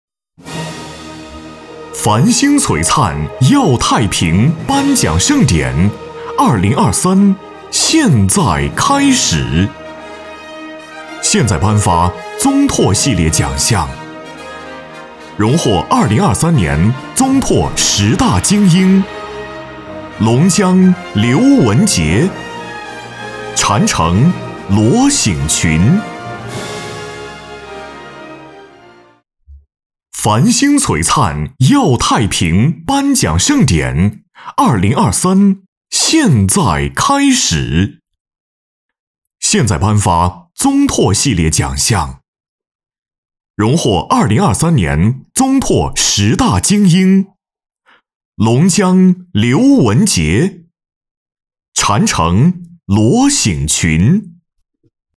男61号配音师
擅长专题宣传、广告、纪录片、走心旁白、飞碟说等多种类型。浑厚大气、年轻活力、温暖走心，多种风格都能驾驭。